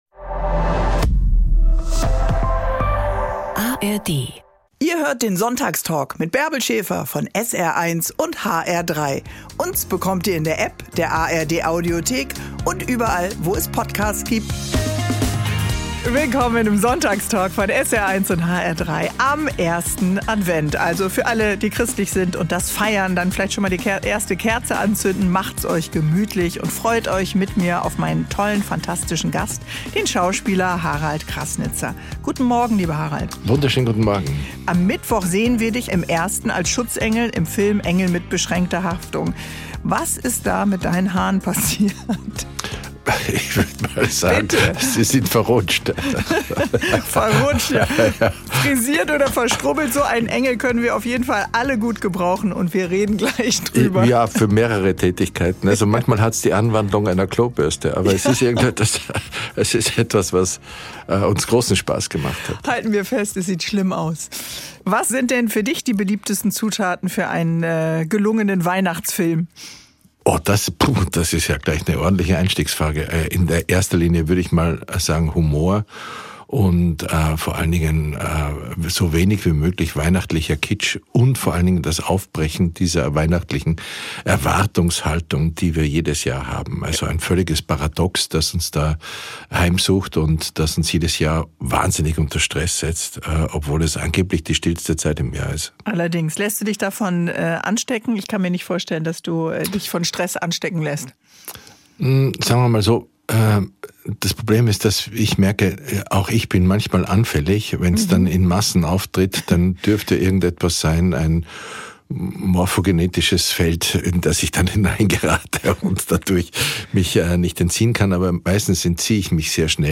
Promis plaudern über Privates, Menschen erzählen ihre besonderen Geschichten – im Sonntagstalk mit Bärbel Schäfer von SR 1 und hr3.
… continue reading 314 एपिसोडस # Bärbel Schäfer # Hr3 # Interviews # Hessischer Rundfunk # Talk # Politik # Interview # Faszinierende Menschen # Gesellschaft # Gespräche